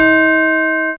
ChimesC1C4.wav